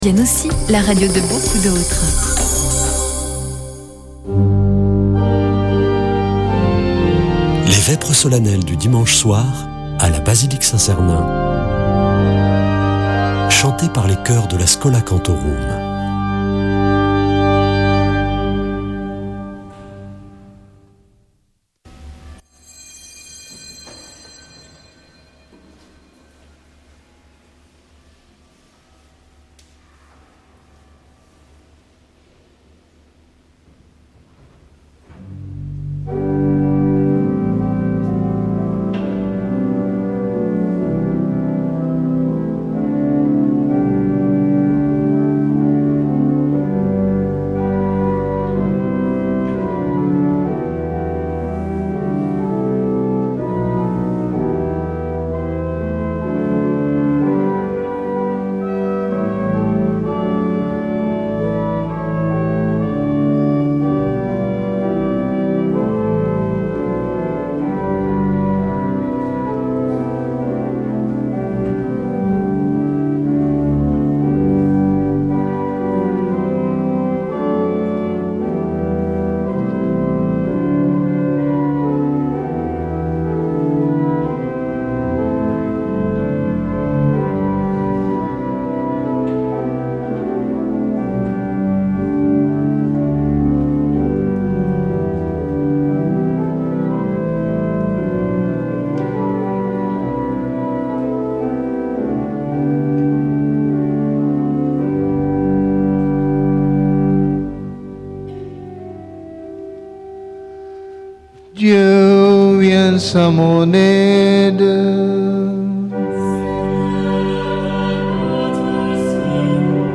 Vêpres de Saint Sernin du 12 avr.
Schola Saint Sernin Chanteurs